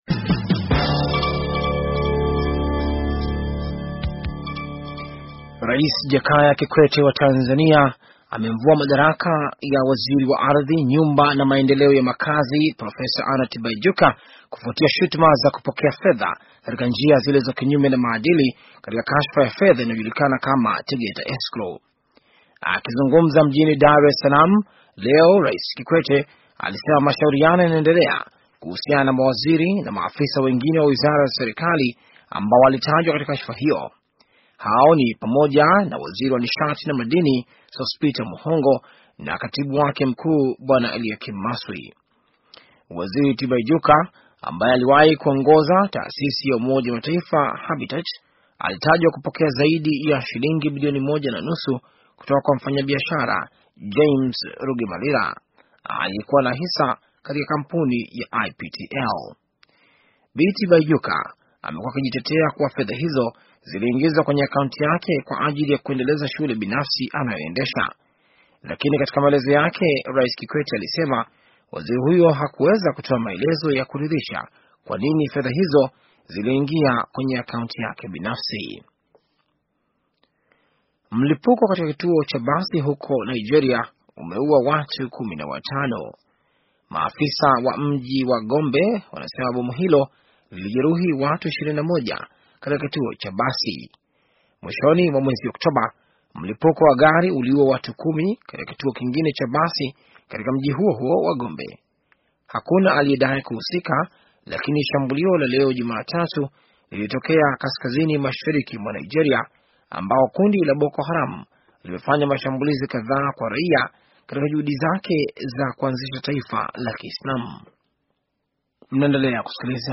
Taarifa ya habari - 5:43